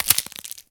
bone_break_neck_snap_crack_01.wav